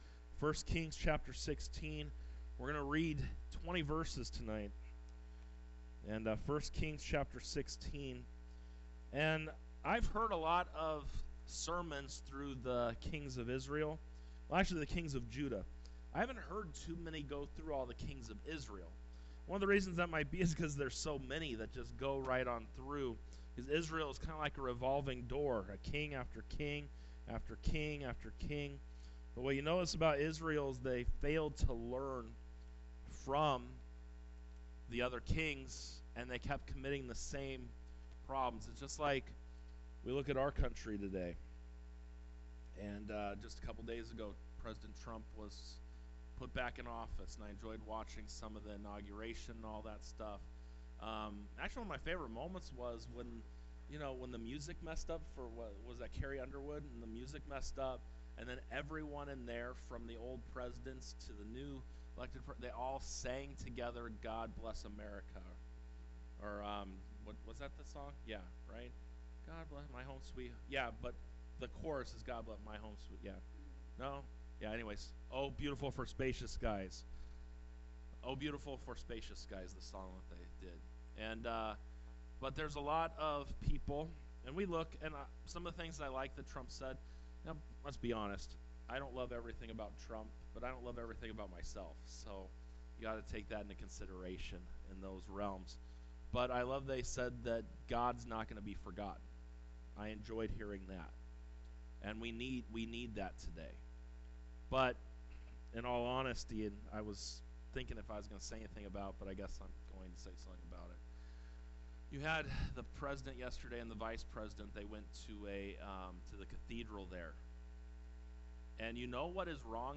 Sermons | Victory Baptist Church